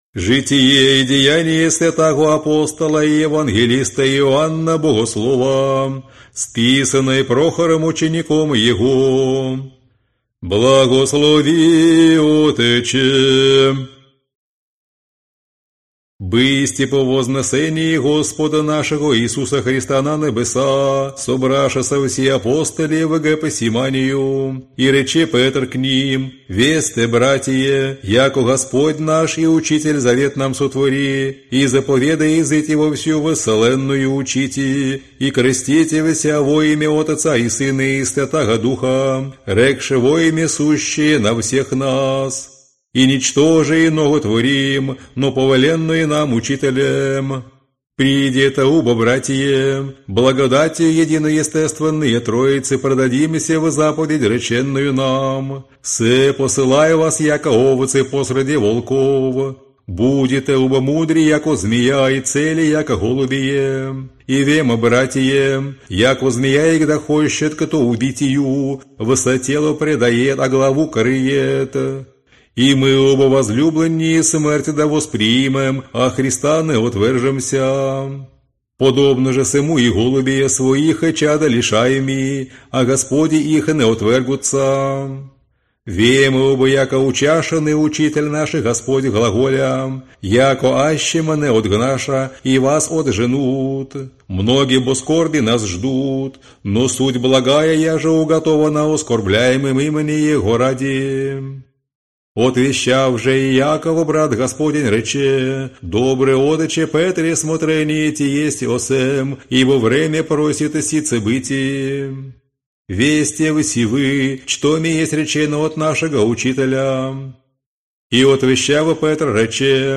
Аудиокнига Знаменный распев. Житие и деяния святого апостола и евангелиста Иоанна Богослова и песнопения ему | Библиотека аудиокниг